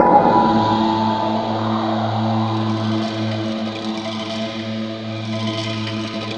SI2 GONGG0BL.wav